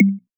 Bounce 2.wav